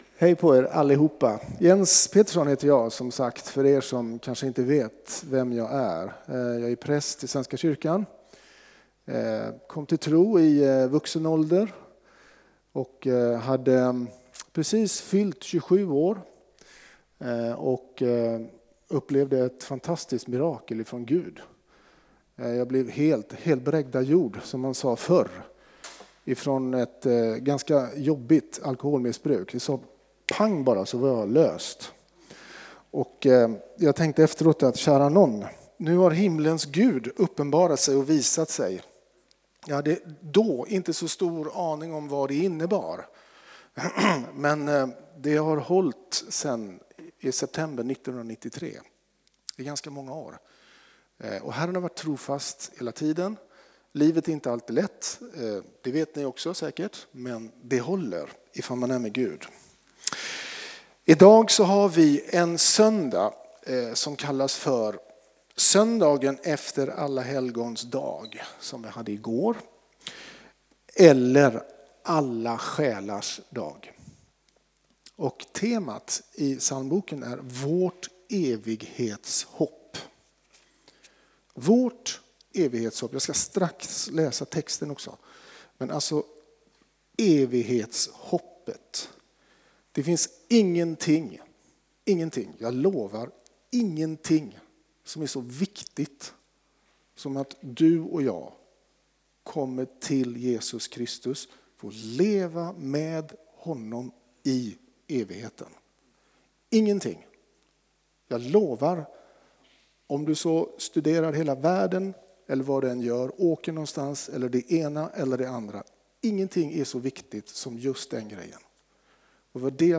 Furuhöjdskyrkan - Predikan